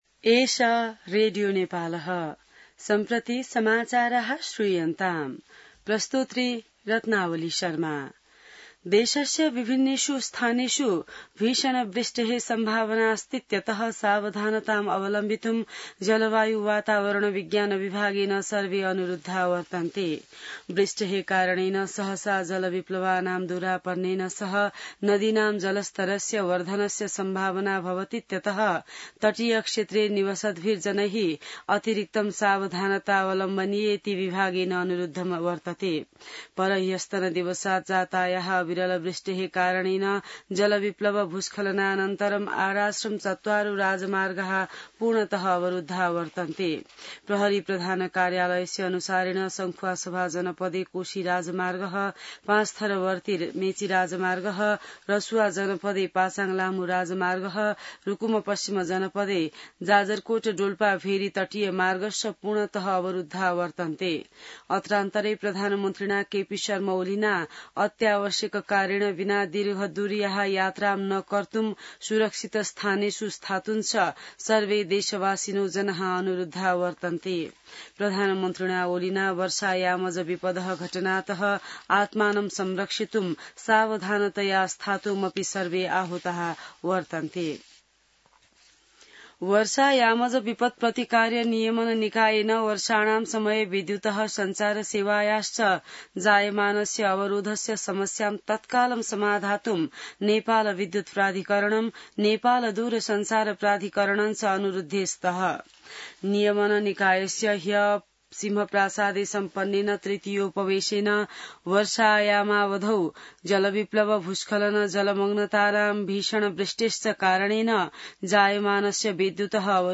संस्कृत समाचार : ५ साउन , २०८२